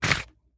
Leather Holster 002.wav